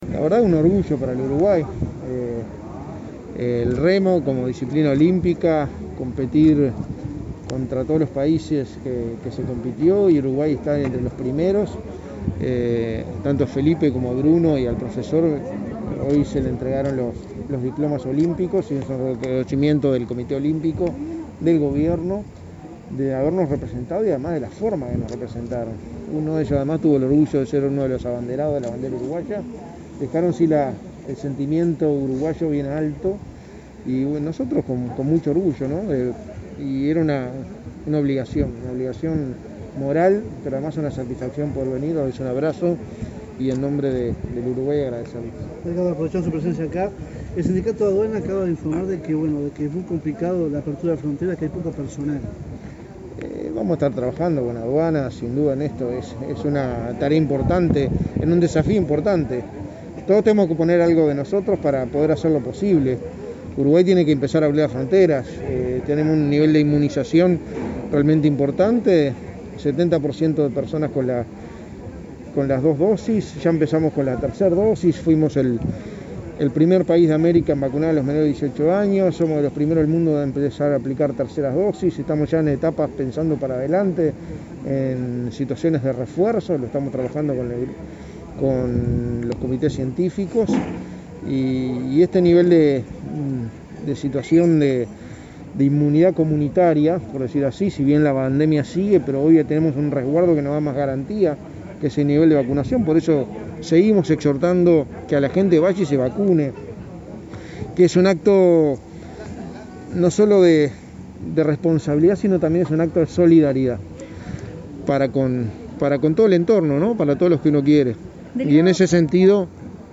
Declaraciones a la prensa del secretario de Presidencia, Álvaro Delgado
Declaraciones a la prensa del secretario de Presidencia, Álvaro Delgado 26/08/2021 Compartir Facebook X Copiar enlace WhatsApp LinkedIn Este jueves 26, el secretario de Presidencia, Álvaro Delgado, participó en la entrega de medallas olímpicas a los remeros Bruno Cetraro y Felipe Klüver y, luego, dialogó con la prensa.